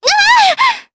Talisha-Vox_Damage_Jp_02.wav